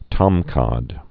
(tŏmkŏd)